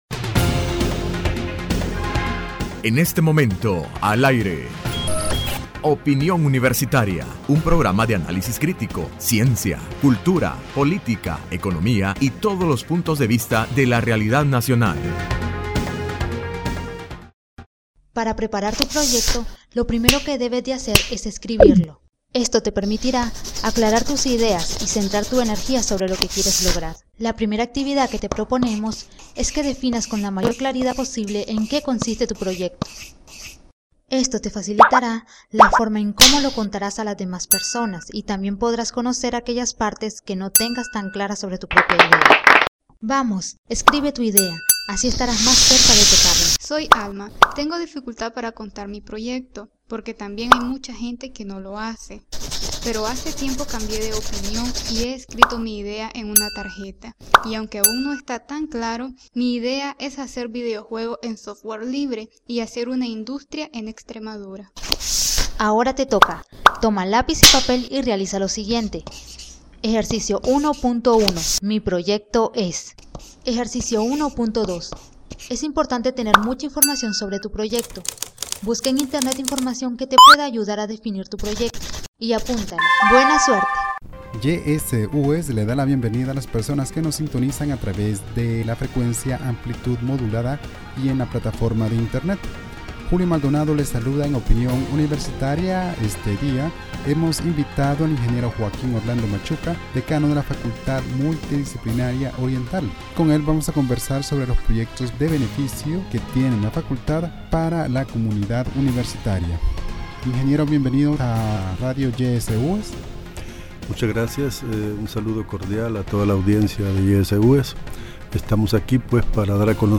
Entrevista Opinión Universitaria (8 de marzo 2016) : Proyectos que tiene la Facultad Multidisciplinaria Oriental para beneficio de la comunidad.